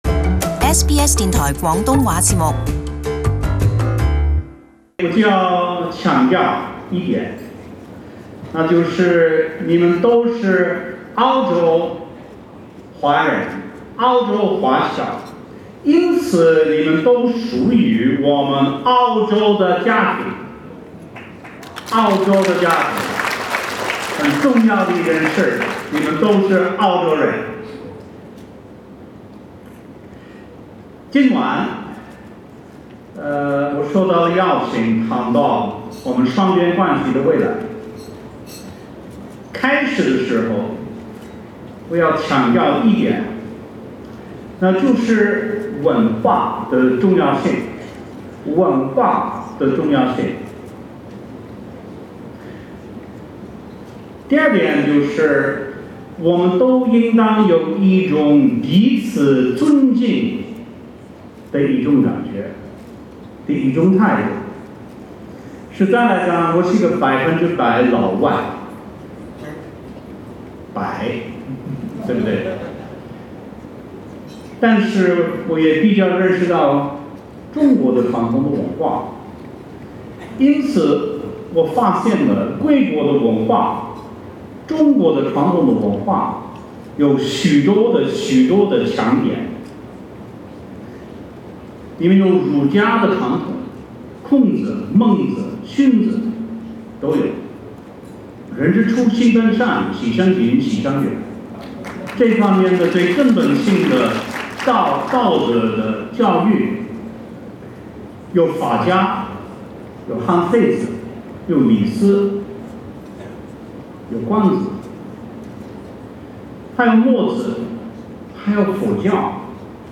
前總理陸克文在10月28日的中澳未來論壇上發表講話。
前總理陸克文（Kevin Rudd）在 10 月 28 日（星期日）出席在雪梨舉行的「中澳未來論壇」，並以一口流利的普通話、圍繞中國及澳洲雙邊關係的主題，發表演說。 他在演說中，首先表達「文化」的重要性及彼此尊重的態度。